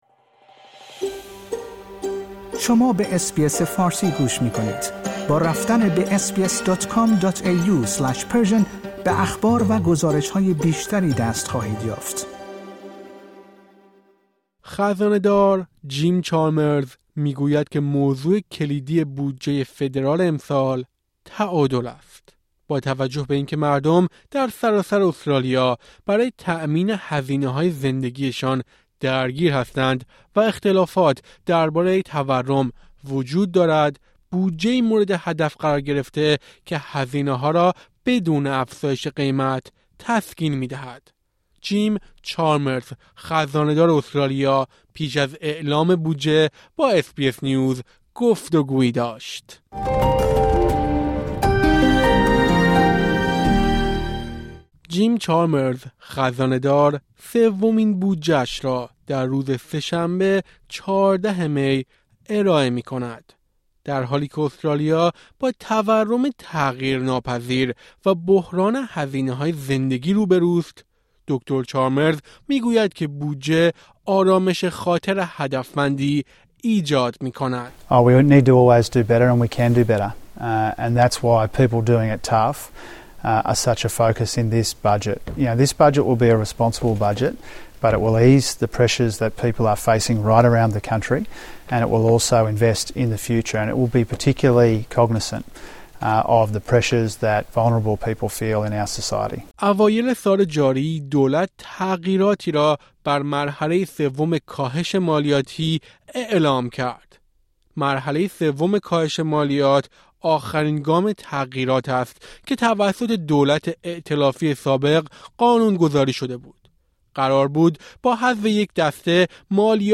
این یک گزارش صوتی است.